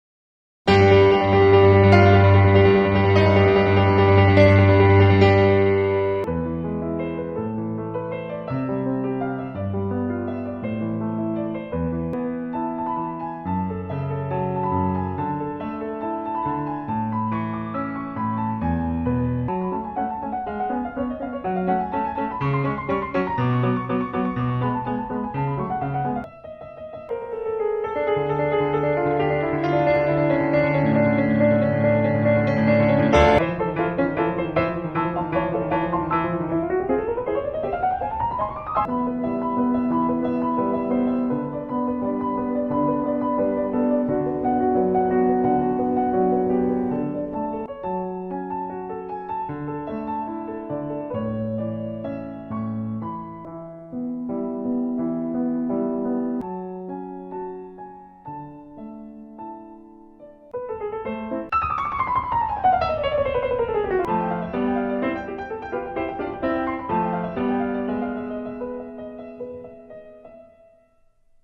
Piano_mashup.mp3